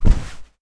Tackle Normal.wav